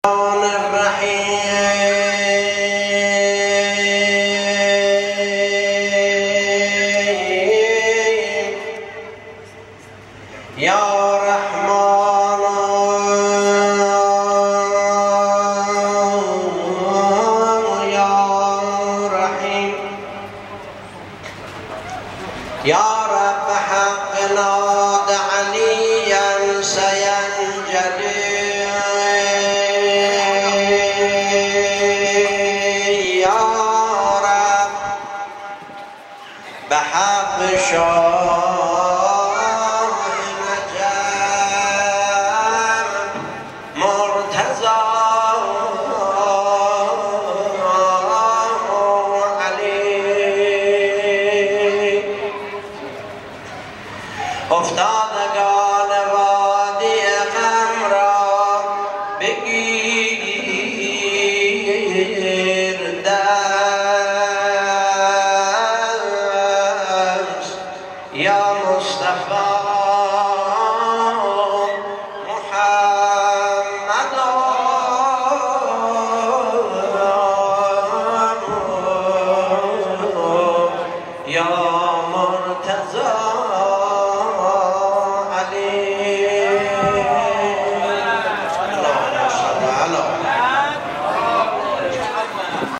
جمعه 12 شهریور 1395 در شب ازدواج حضرت امیرمؤمنان علی بن ابی طالب علیه السلام و سرور زنان بهشتی حضرت زهرا سلام الله علیها در مسجد جامع جشن و مدح خوانی اهلبیت علیهم السلام داشتیم.
مدح خوانی